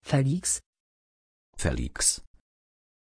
Pronuncia di Feliks
pronunciation-feliks-pl.mp3